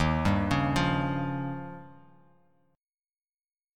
Eb9 Chord
Listen to Eb9 strummed